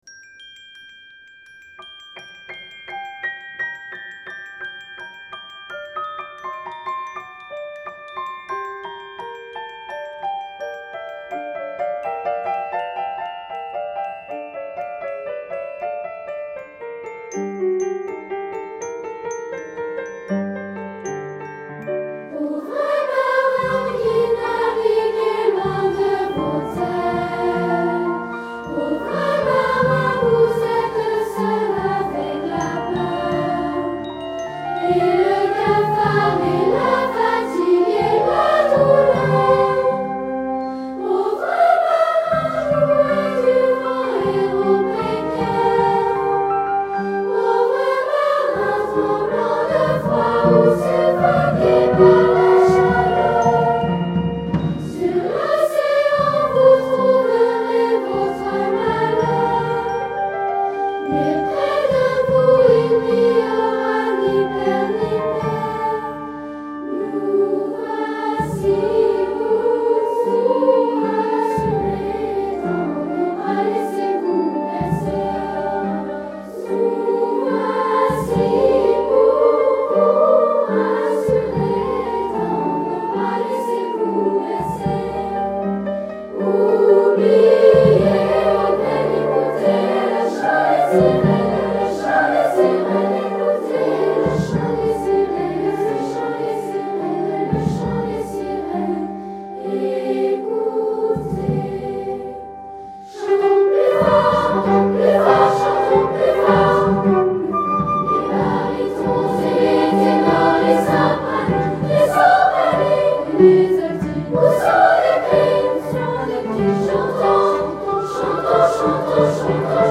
Genre-Style-Forme : Profane ; Fantaisie
Type de choeur : SSA  (3 voix égale(s) d'enfants )
Tonalité : sol mineur